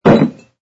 sfx_put_down_bottle03.wav